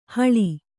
♪ haḷi